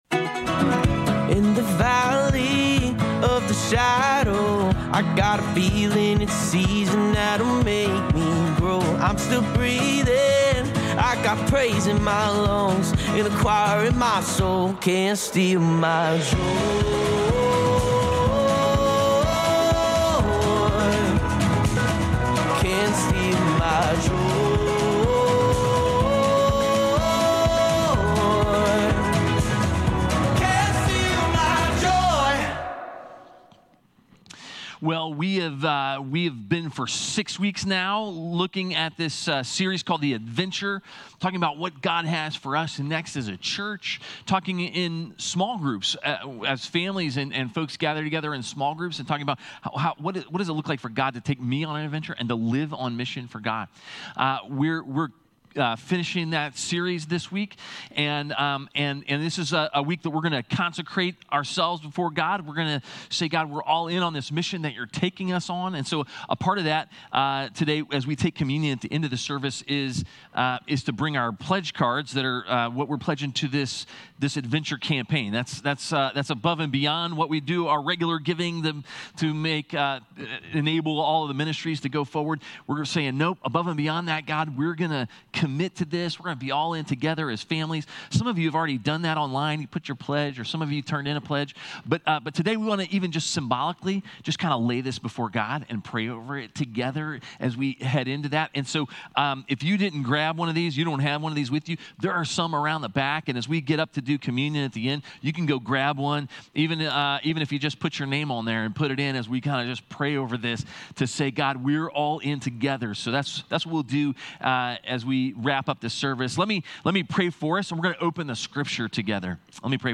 Sermons | Advent Presbyterian Church